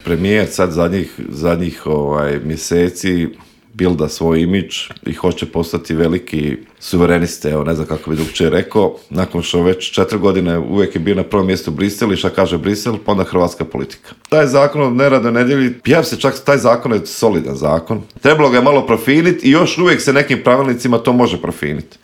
Samo su neka od pitanja na koje smo u Intervjuu Media servisa odgovore potražili od saborskog zastupnika iz Domovinskog pokreta Marija Radića koji je najavio veliki politički skup stranke 23. ožujka u Zagrebu.